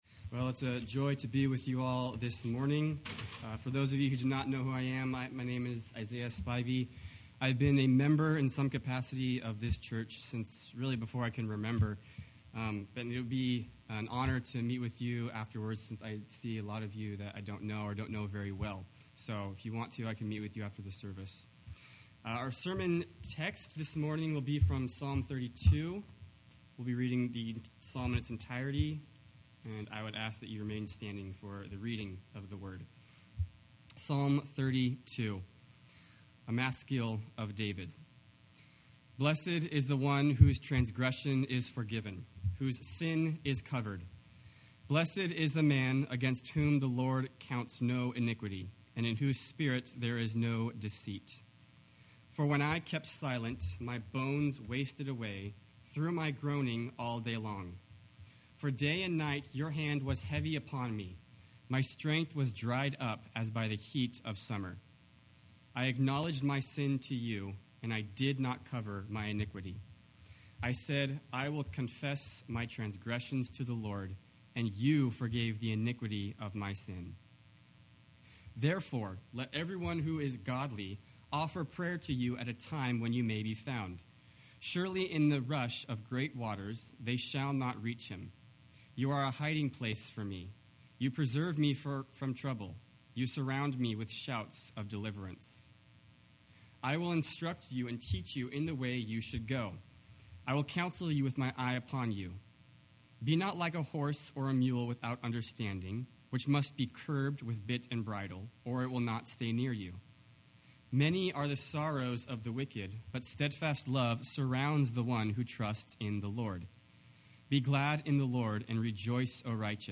Psalm 32 Service Type: Morning Main Point